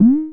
jump.mp3